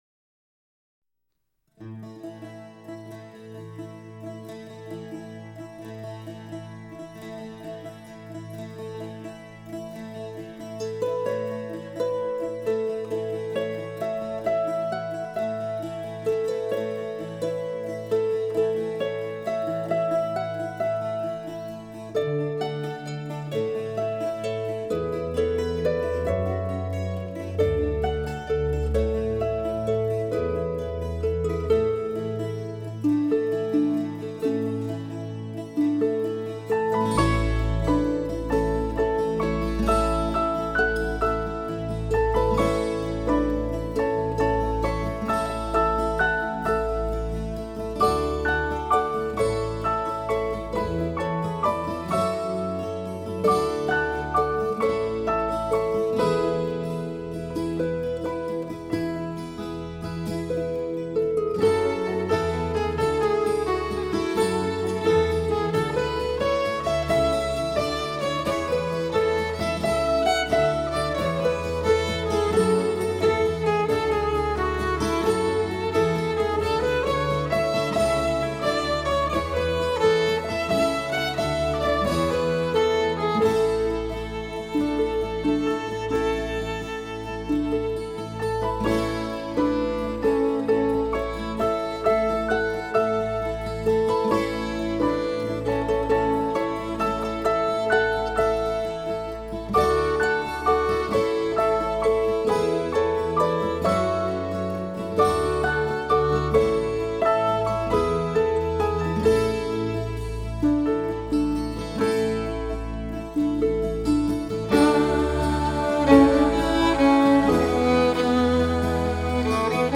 愿这宁静乐曲能给各位朋友带来好心情。